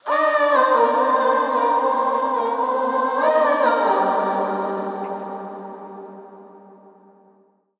LYRE_vocal_group_female_wet_haunting_Cmin
LYRE_vocal_group_female_wet_haunting_Cmin.wav